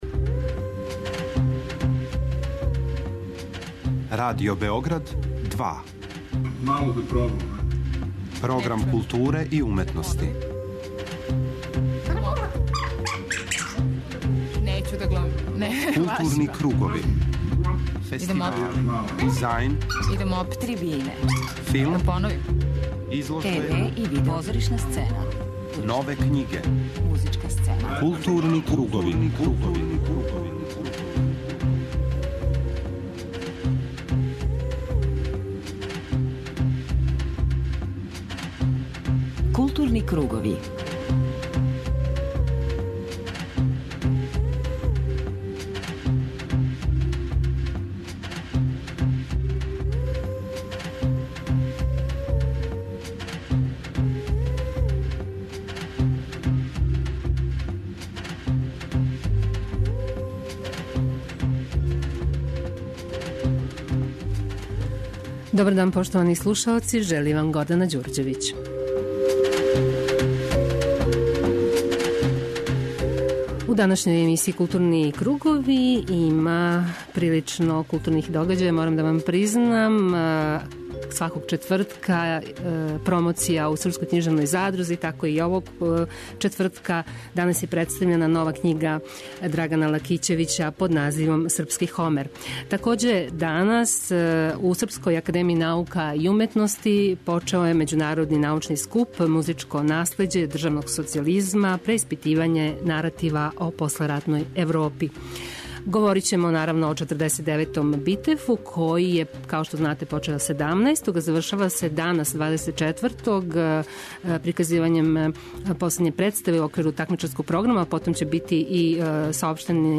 У Маскама ћете чути Оливера Фрљића, контроверзног редитеља из Хрватске, поводом представе "Комплекс Ристић" која је била приказана у такмичарском делу Битефа (који се завршава 24. септембра).
преузми : 53.48 MB Културни кругови Autor: Група аутора Централна културно-уметничка емисија Радио Београда 2.